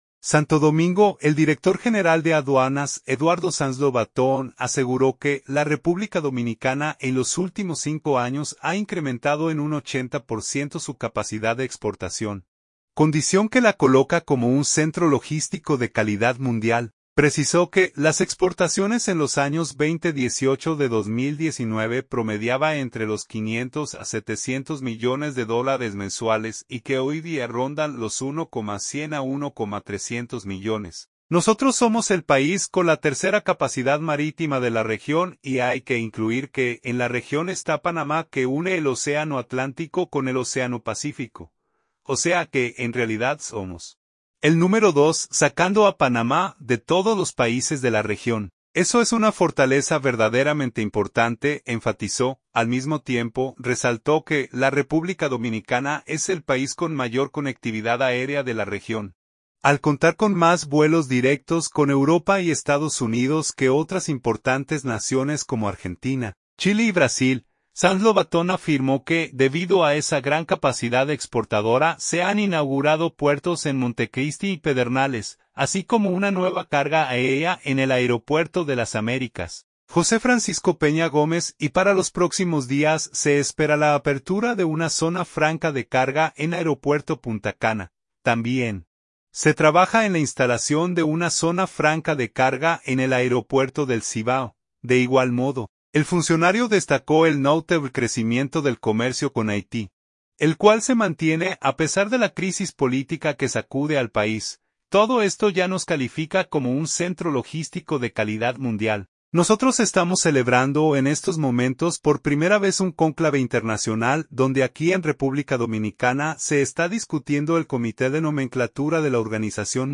“Todo esto ya nos califica como un centro logístico de calidad mundial. Nosotros estamos celebrando en estos momentos por primera vez un cónclave internacional donde aquí en República Dominicana se está discutiendo el comité de nomenclatura de la Organización Mundial de las Aduanas. La nomenclatura es ponernos de acuerdo en los términos de los aranceles y se celebra aquí porque hay un reconocimiento a la gestión logística del país”, apuntó al participar en el programa Despierta con la X, por la emisora X 102.